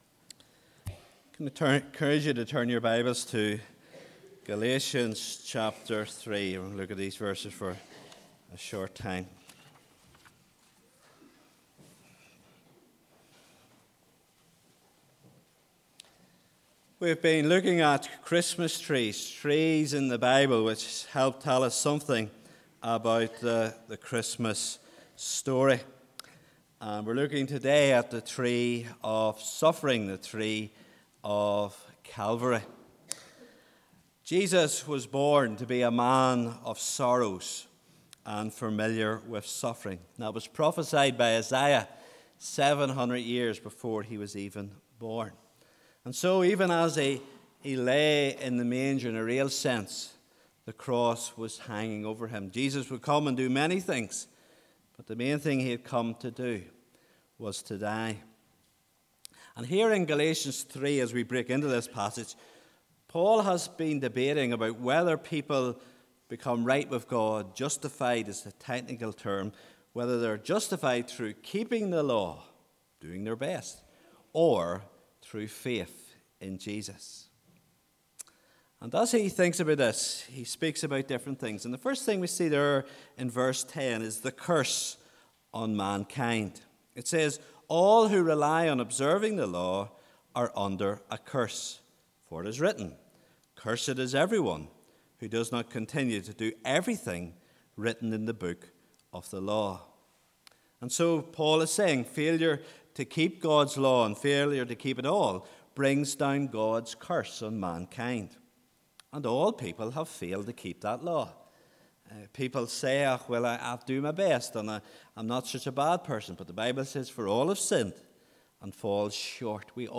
Footnotes [1] 3:11 Or The one who by faith is righteous will live [2] 3:14 Greek receive the promise of the Spirit ( ESV ) Please note: this sermon was preached before Brookside adopted the English Standard Version as our primary Bible translation, the wording above may differ from what is spoken on the recording.